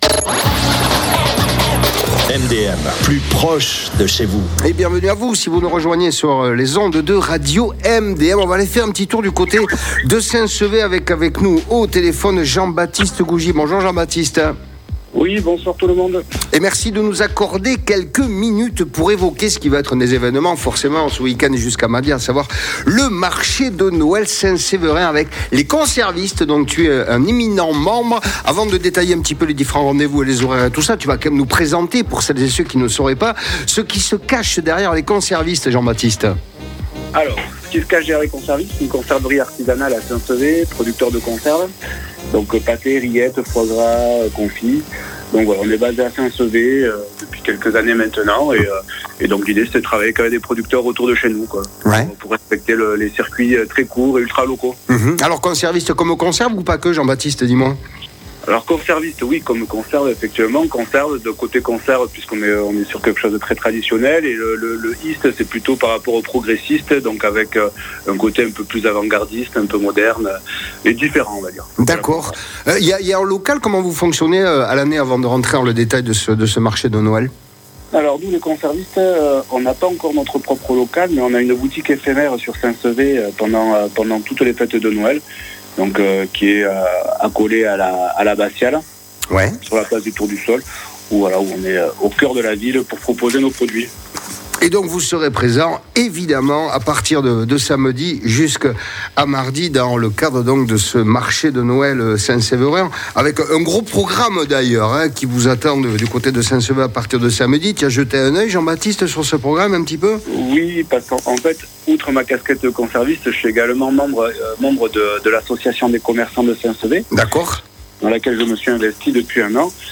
Jeanot Sur La Coline | ITV Noël à Saint-Sever